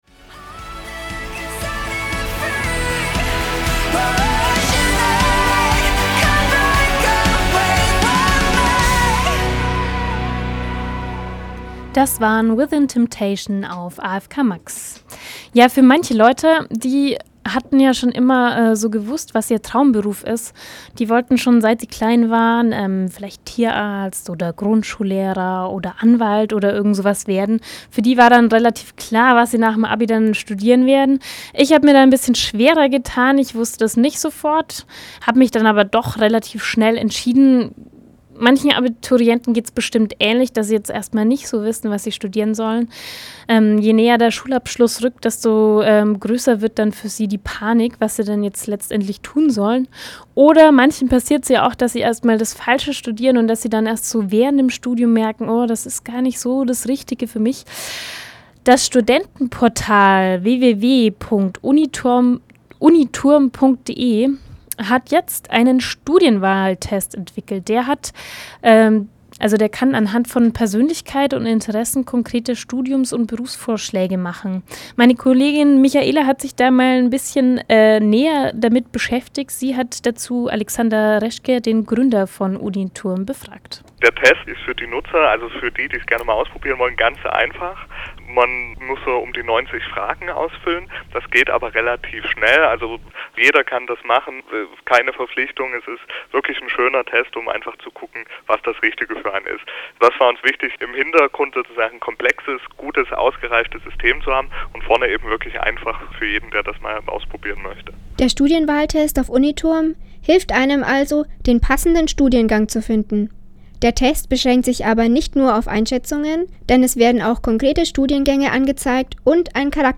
Radio-Interview zum Studienwahltest auf Radio afk max